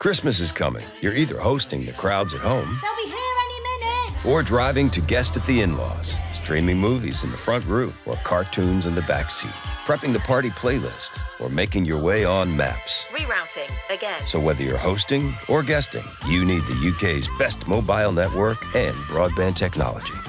This Christmas advert is voiced by Kevin Bacon — but which brand does it belong to?
EE-christmas-ad.mp3